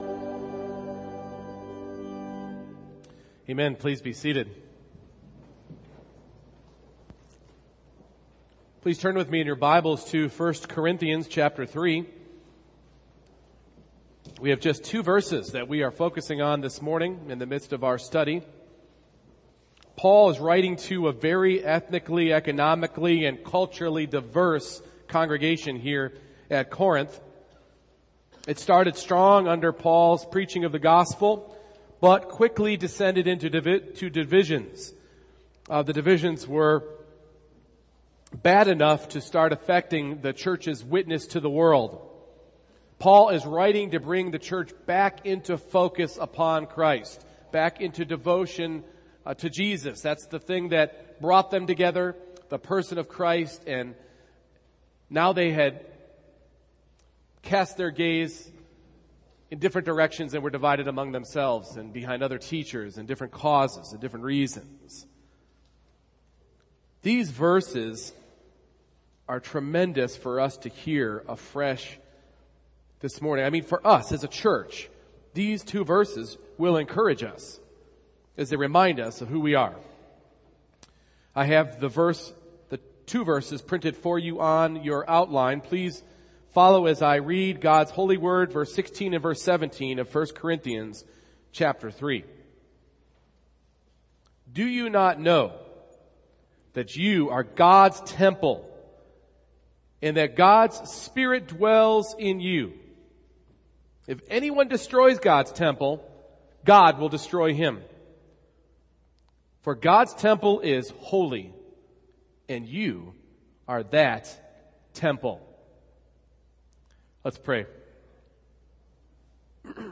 1 Corinthians 3:16-17 Service Type: Morning Worship We are Christ’s Church